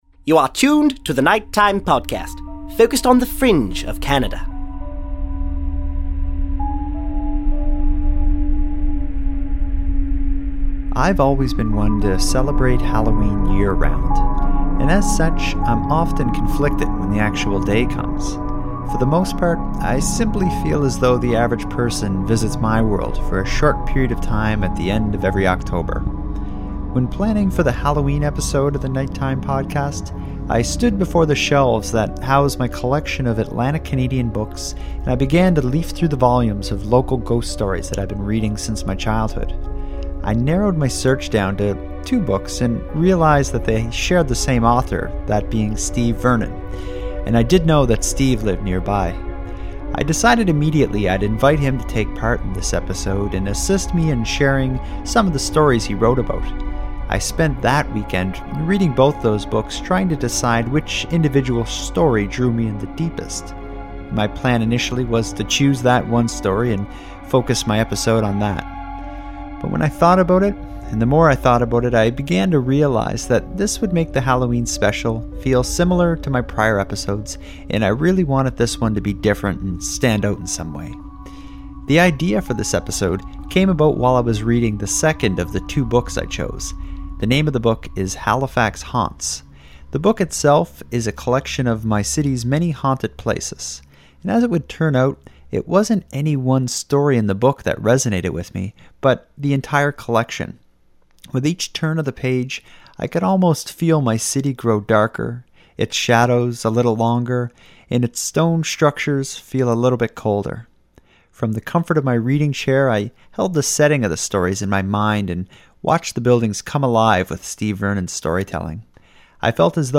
In this Halloween special, I take you along for the ride with us to The Halifax Citadel, the old Spring Garden Road Library, Shirreff Hall, Halifax Club, and The Ashburn Golf Club.